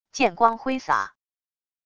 剑光挥洒wav音频